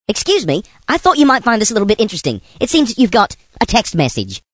SMS Tone > MessageTones Voice